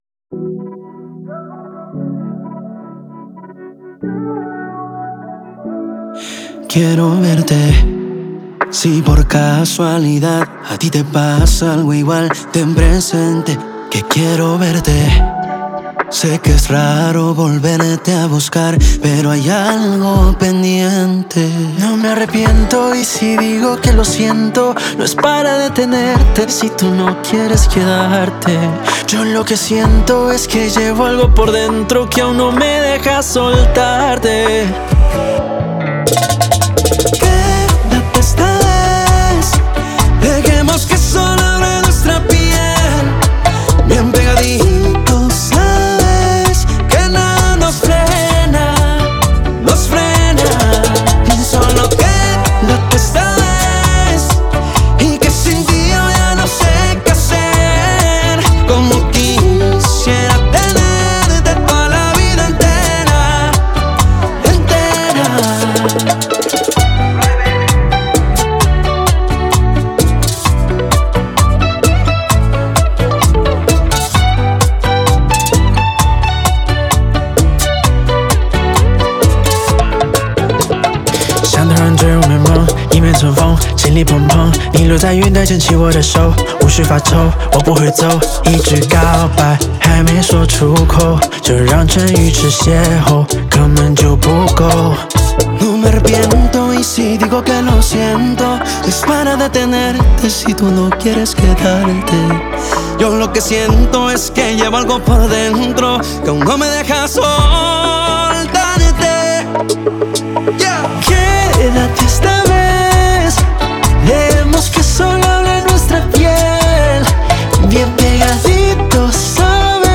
la primera bachata en “chiñol”